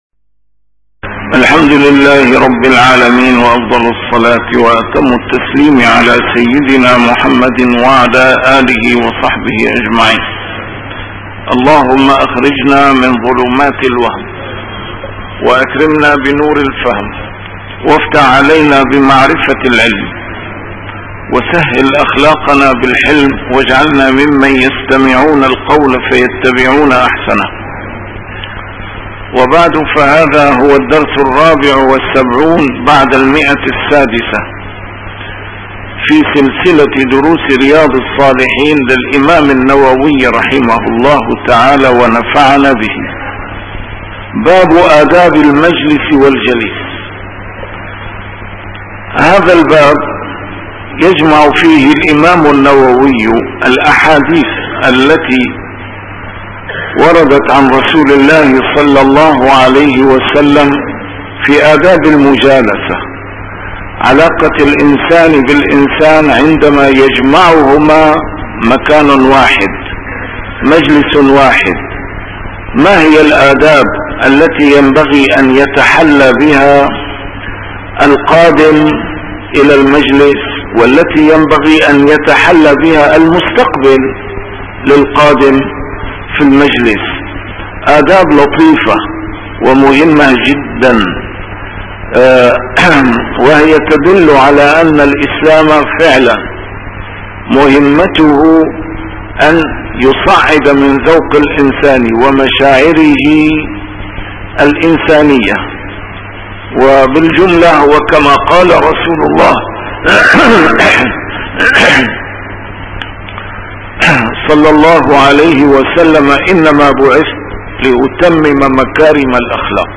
A MARTYR SCHOLAR: IMAM MUHAMMAD SAEED RAMADAN AL-BOUTI - الدروس العلمية - شرح كتاب رياض الصالحين - 674- شرح رياض الصالحين: آداب المجلس والجليس